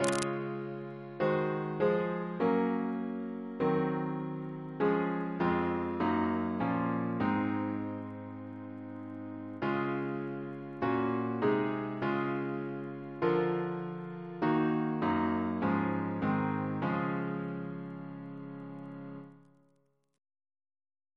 Double chant in D♭ Composer: Charles Harford Lloyd (1849-1919), Organist of Gloucestor Cathedral amd Christ Church, Oxford, Precentor of Eton, Organist and composer to the Chapel Royal Reference psalters: RSCM: 91